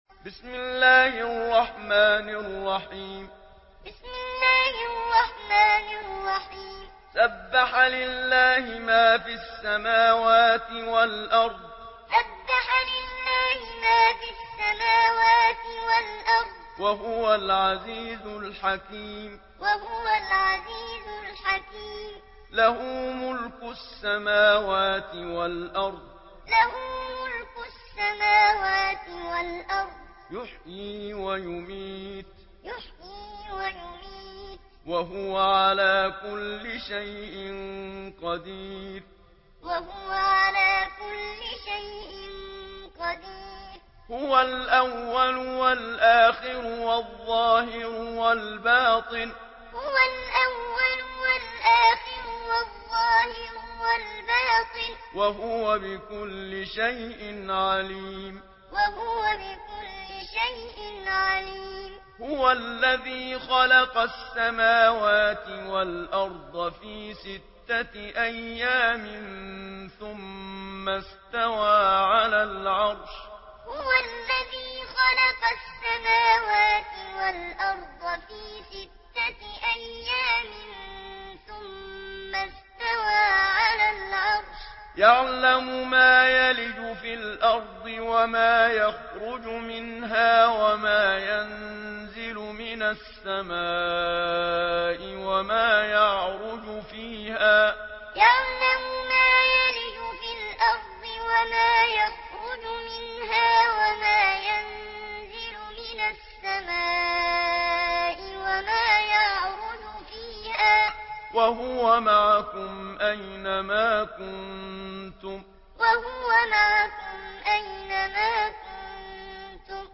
Surah Al-Hadid MP3 in the Voice of Muhammad Siddiq Minshawi Muallim in Hafs Narration
Surah Al-Hadid MP3 by Muhammad Siddiq Minshawi Muallim in Hafs An Asim narration.